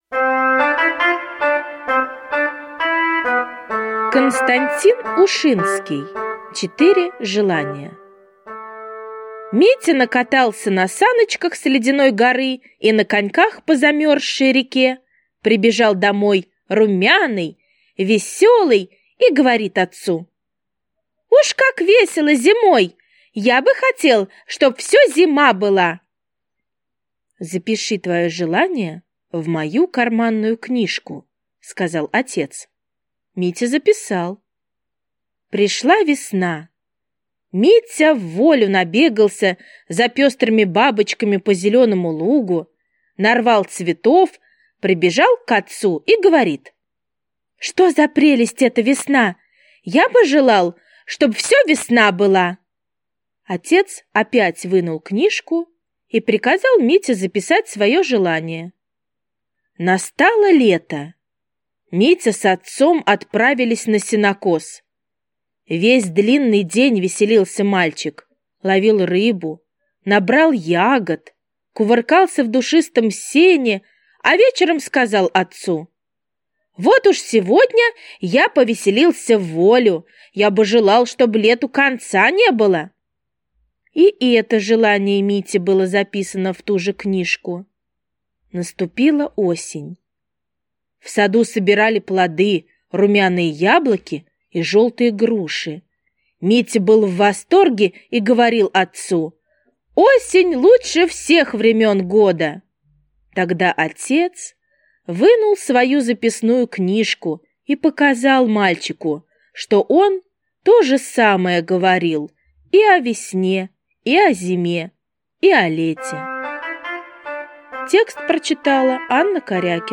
Четыре желания - аудио рассказ Ушинского - слушать онлайн